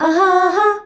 AHAAA   E.wav